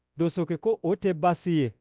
Synthetic_audio_bambara